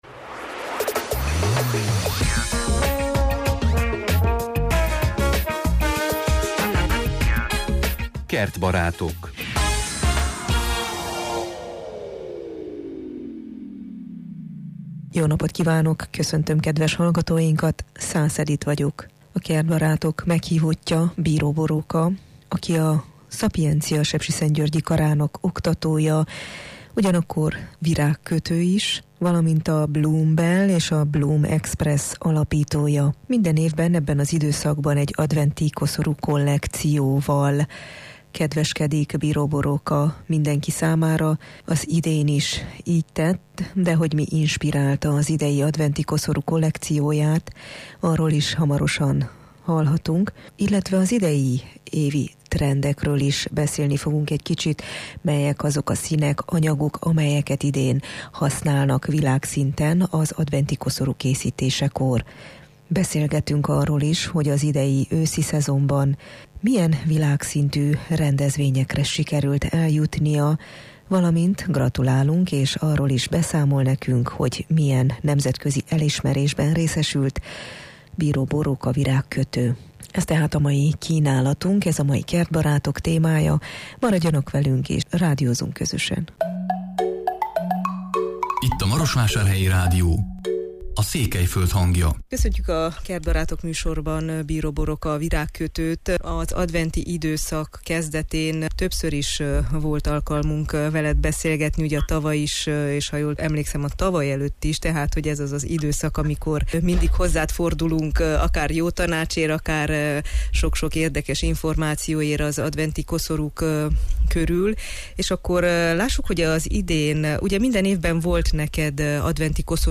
Az idei kollekció a fenntarthatóság jegyében készült, monokróm színekben, organikus alapanyagokból. De beszélgetünk, arról is hogy mi a trend az idei dekorációkkal kapcsolatosan, és arról is beszámolunk, hogy milyen nemzetközi elismerésben részesült a meghívottunk.